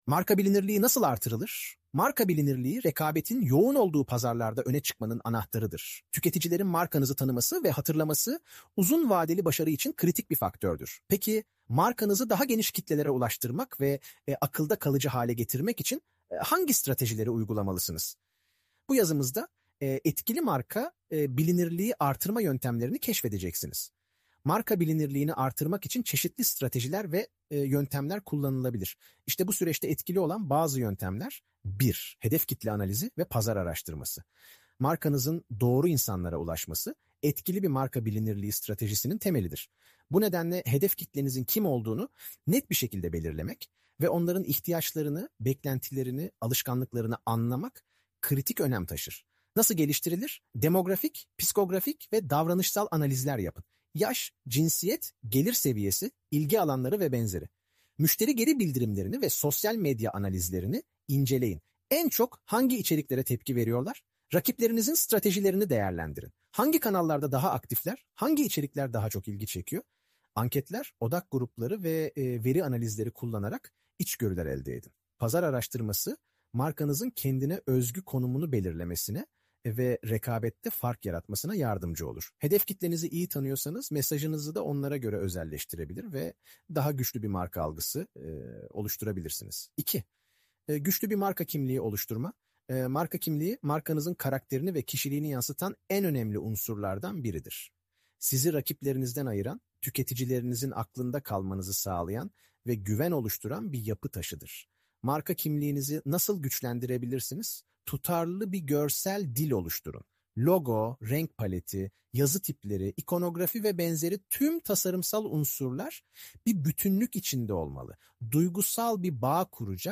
marka-bilinirligi-seslendirme-.mp3